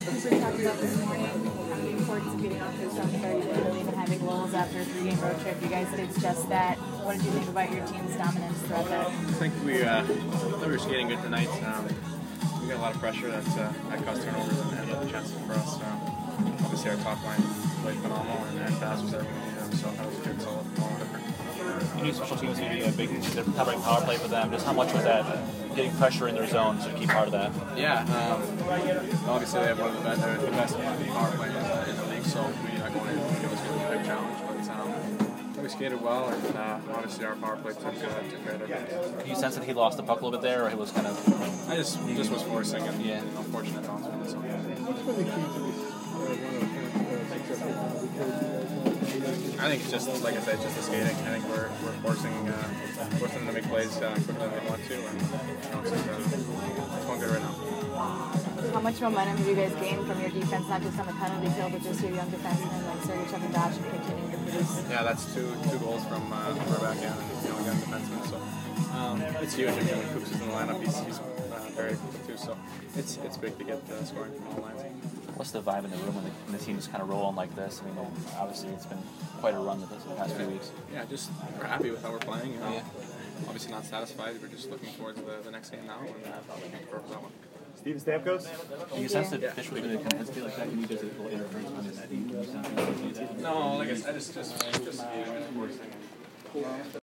Brayden Point Post-Game 11/16